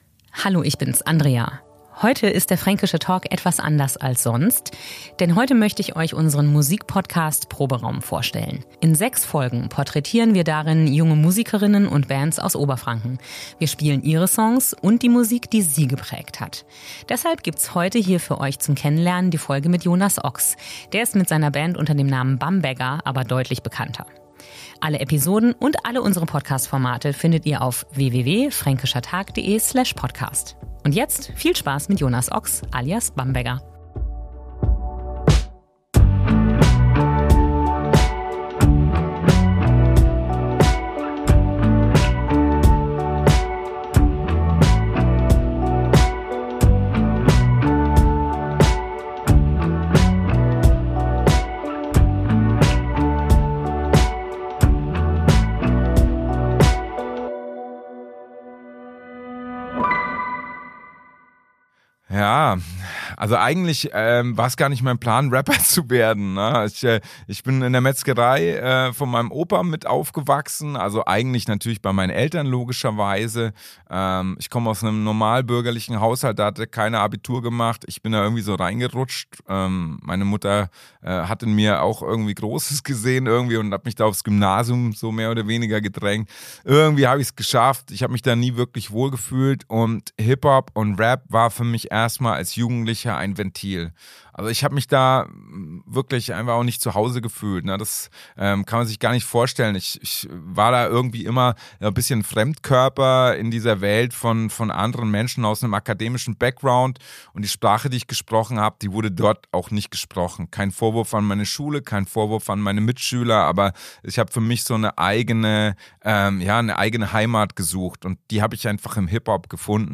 Der Interview-Podcast